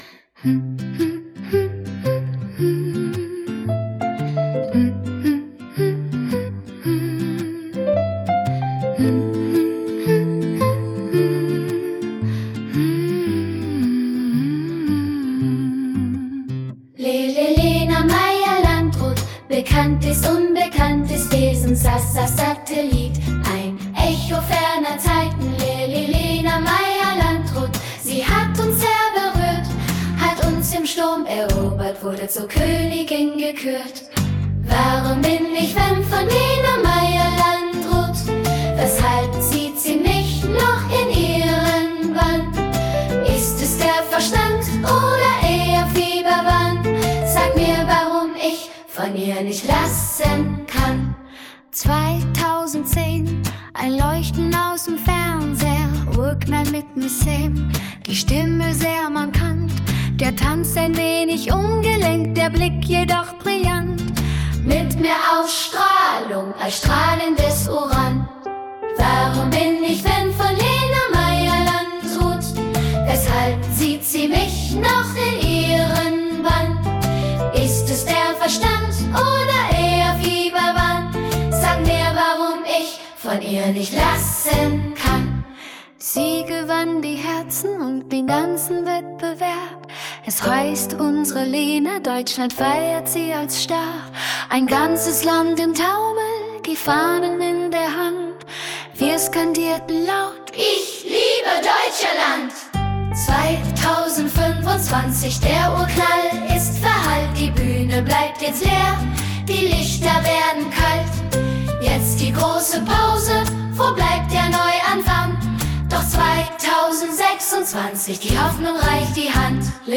Der (Lena-) Fan – Kinderchor, akustisches Piano, akustische Gitarre
Der-Lena_Fan-_Kinder-Chor_.mp3